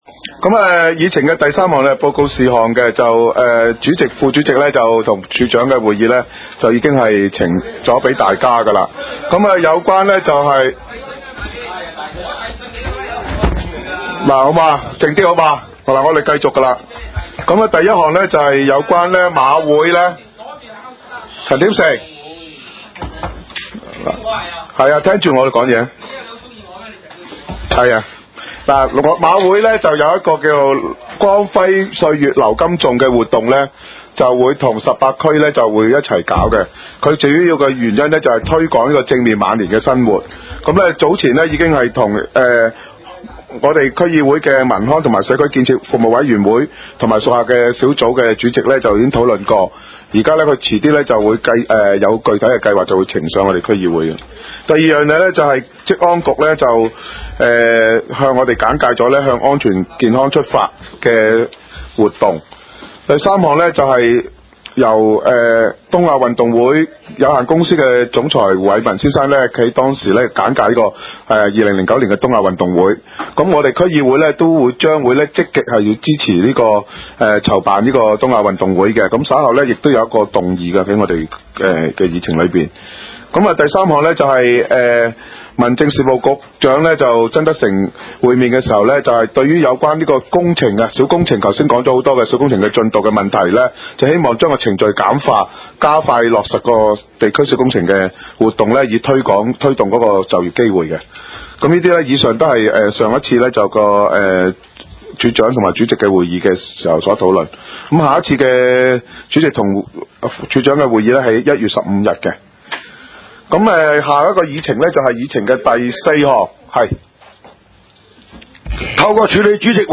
東區區議會會議室